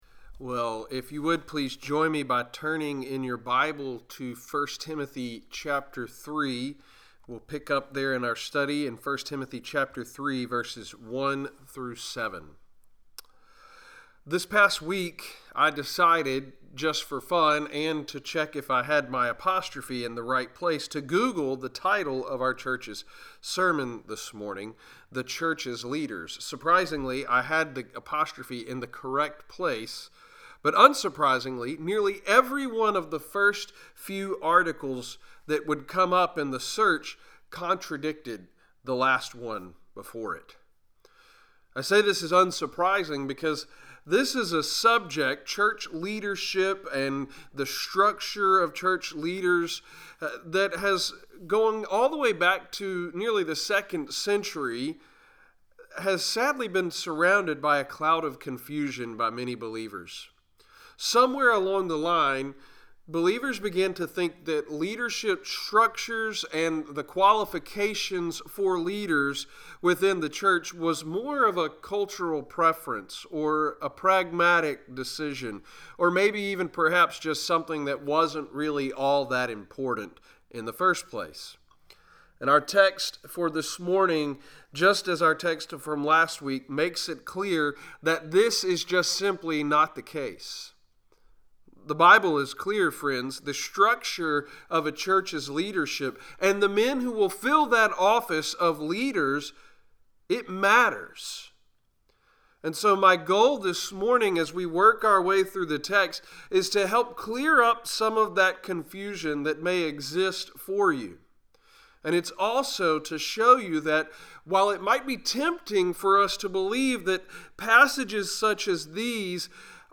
**Note: this is a rerecording, as the original sermon wasn't recorded in its entirety.**